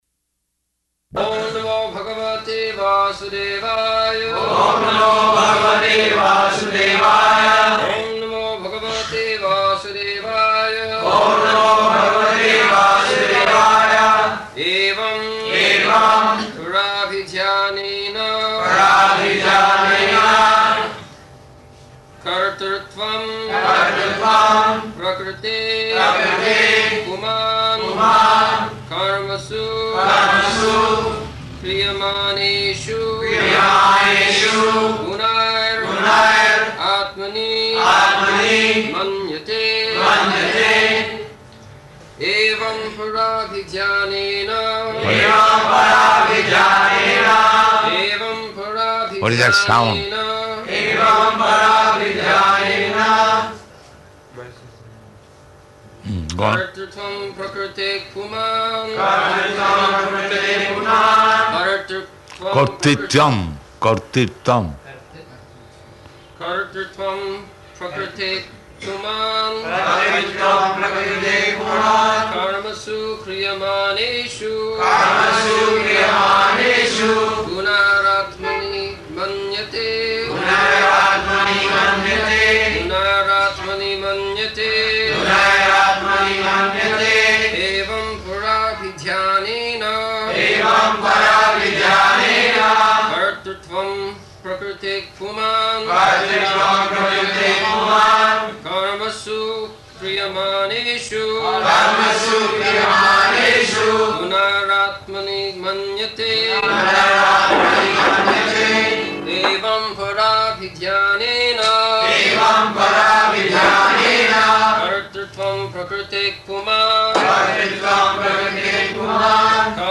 -- Type: Srimad-Bhagavatam Dated: December 18th 1974 Location: Bombay Audio file